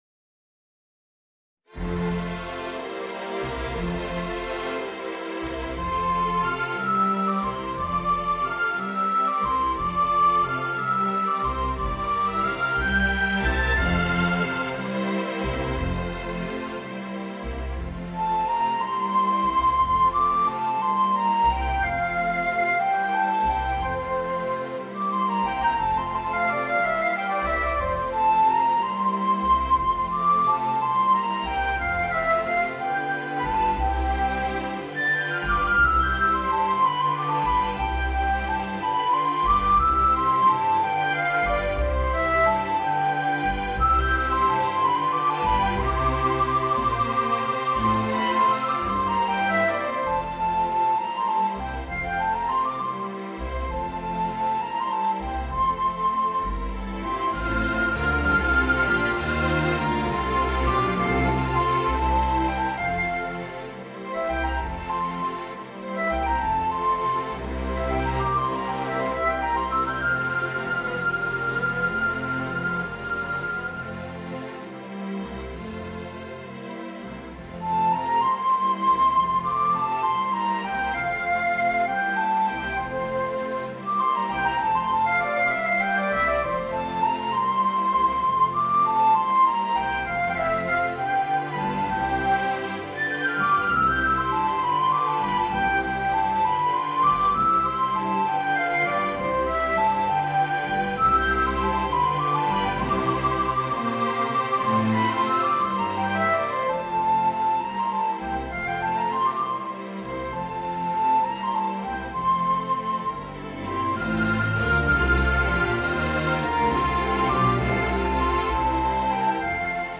Voicing: Trumpet and String Orchestra